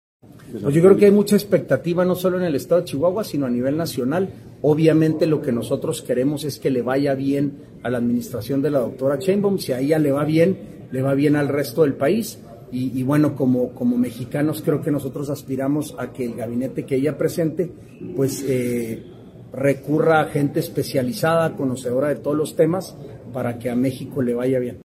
AUDIO: SANTIAGO DE LA PEÑA GRAJEDA, SECRETARIO GENERAL DE GOBIERNO (SGG)